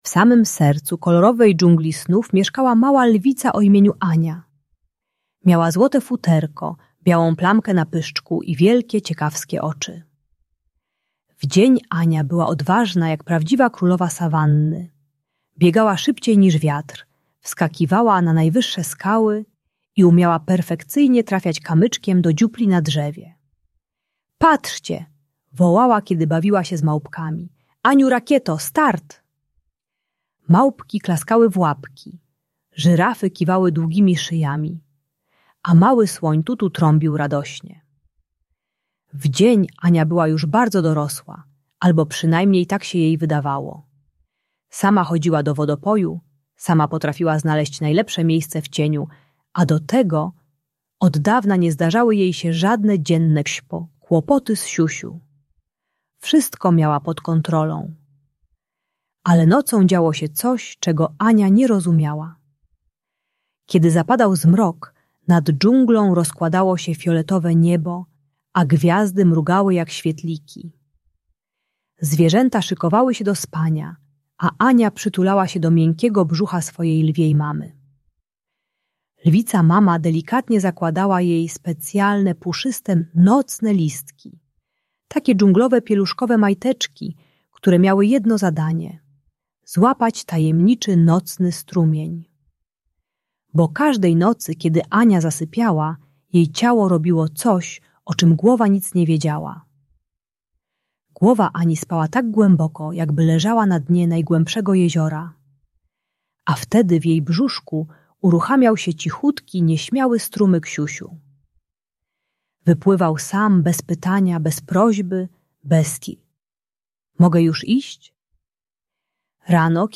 Historia małej lwicy Ani w dżungli snów - Trening czystości | Audiobajka